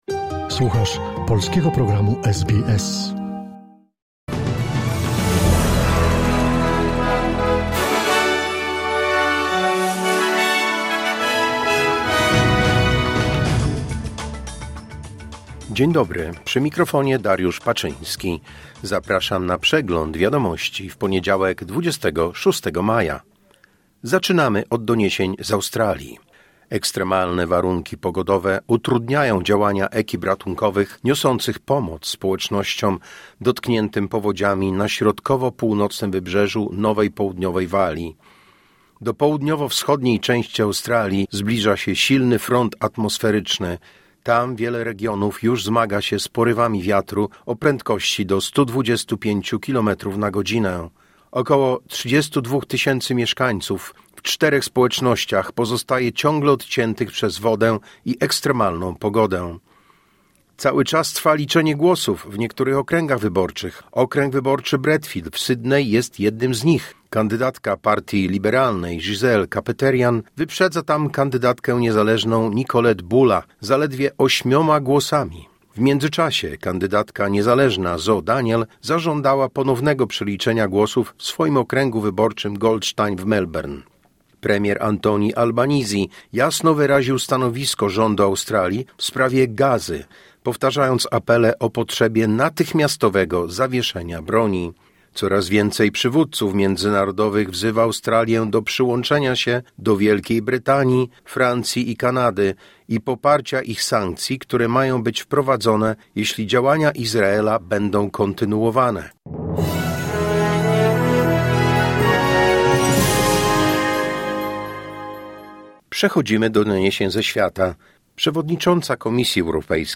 Wiadomości 26 maja SBS News Flash